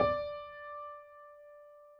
piano_062.wav